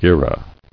[ge·rah]